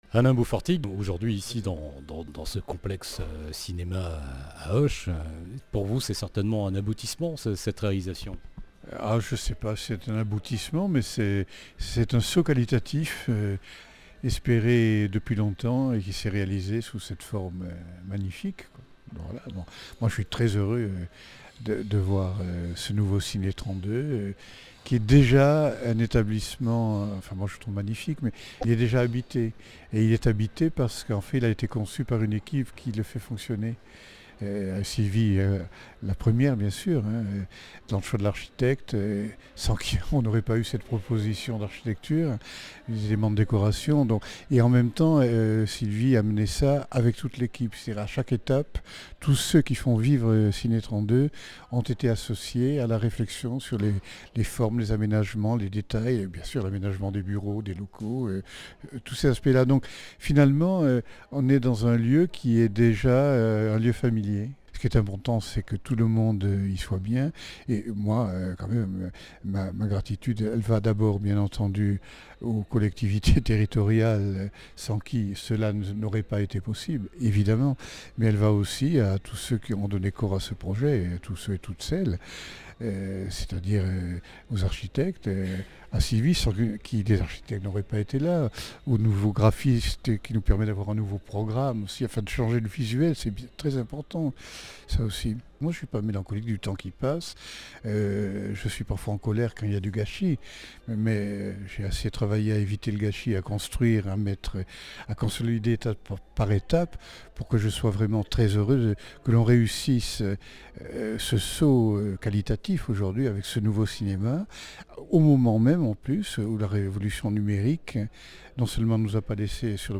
Fête inaugurale du nouveau complexe Ciné 32 – Ruchemania